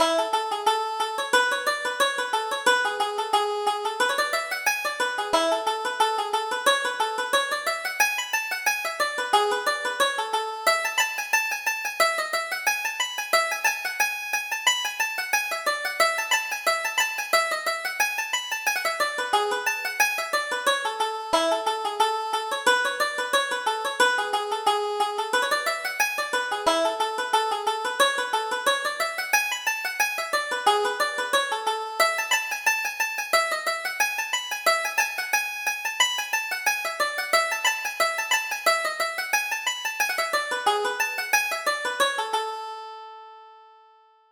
Reel: A Rainy Day